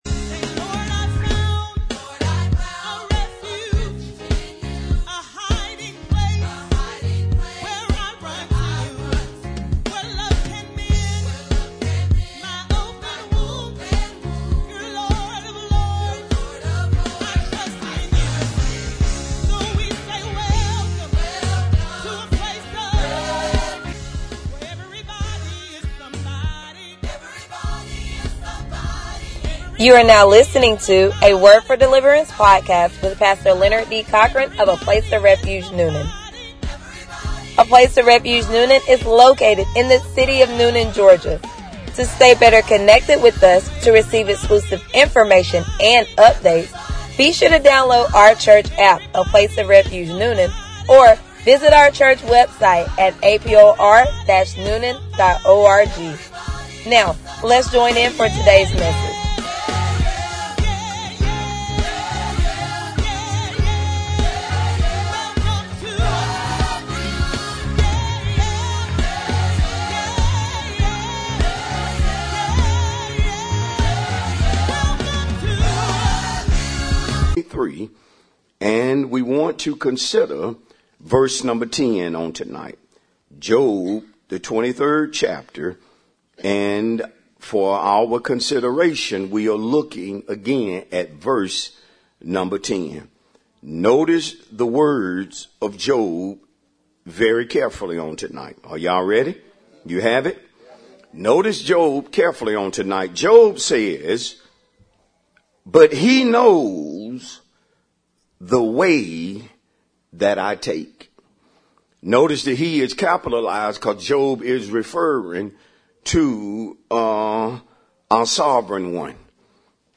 Sermons | A Place Of Refuge Newnan